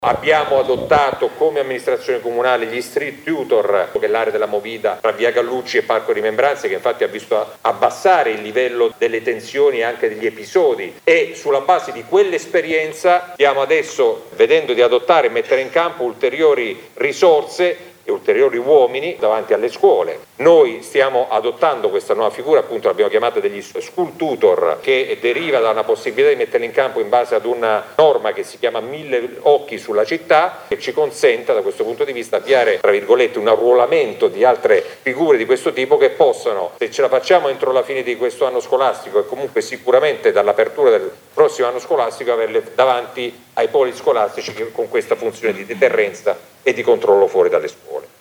Il sindaco Mezzetti ha illustrato alcune misure che dovranno essere adottate: ha parlato degli school tutor, ma anche di un raddoppio del presidio in autostazione, dalle 12 alle 14, della polizia locale. Dalla prossima settimana poi la Tenda verrà aperta dalle 13 alle 15 per offrire ai ragazzi un luogo sicuro in cui stare, in attesa del proprio autobus.